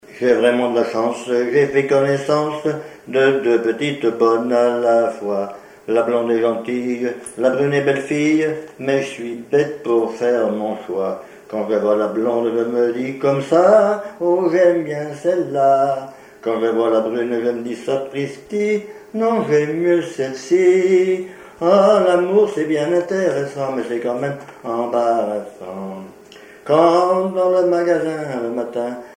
Genre strophique
Répertoire au violon
Pièce musicale inédite